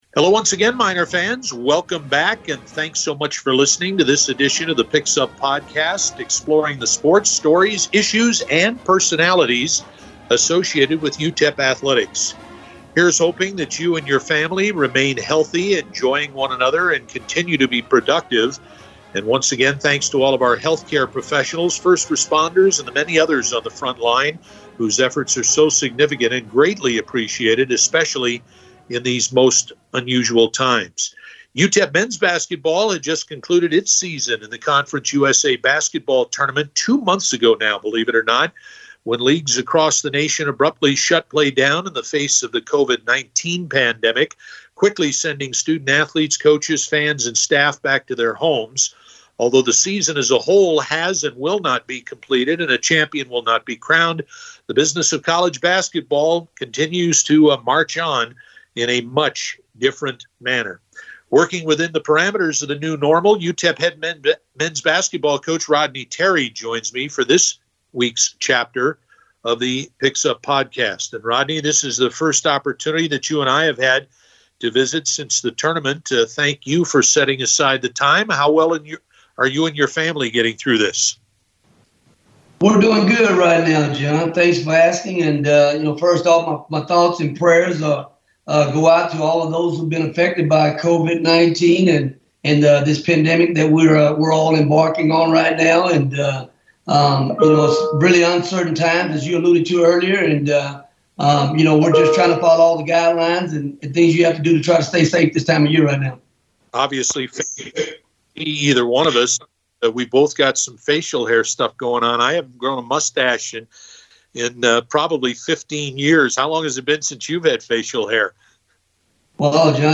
visits with men's basketball coach Rodney Terry